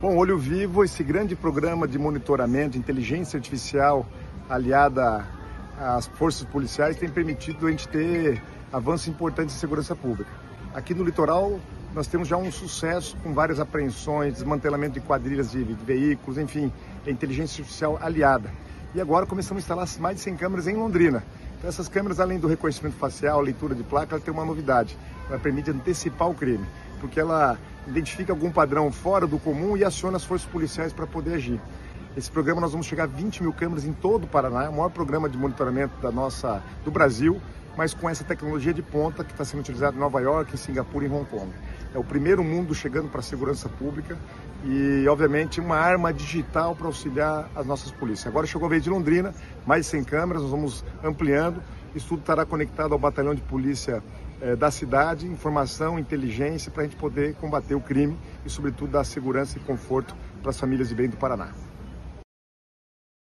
Sonora do secretário das Cidades, Guto Silva, sobre o início da instalação de câmeras do programa de monitoramento Olho Vivo em Londrina